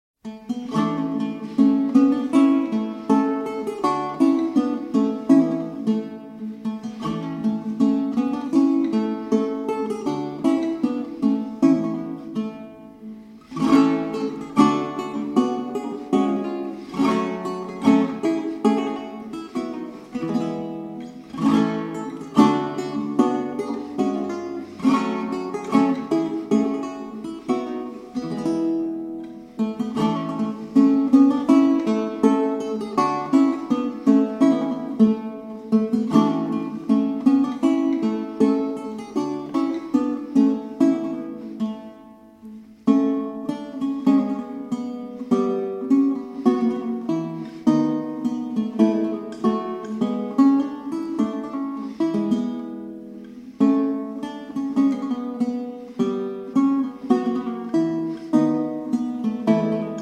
performed on baroque guitar.